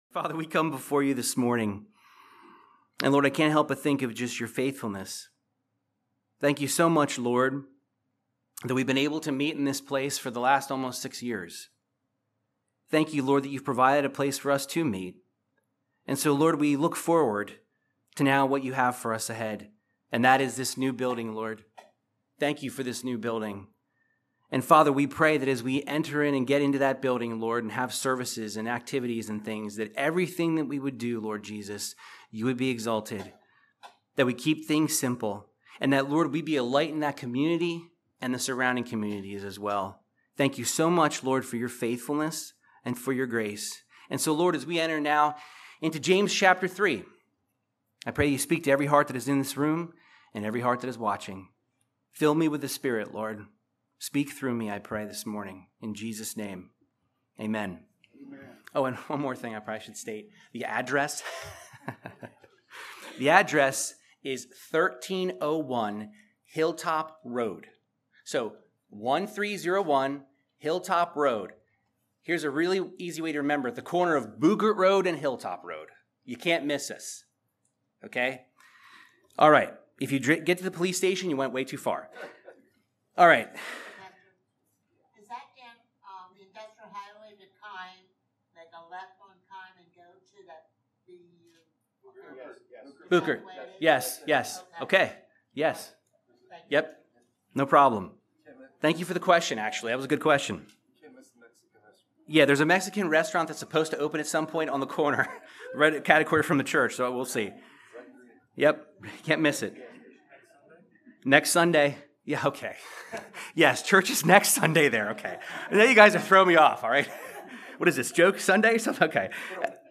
Verse By Verse Bible Teaching from James chapter 3 discussing our speech and how our words show the kind of person we are on the inside.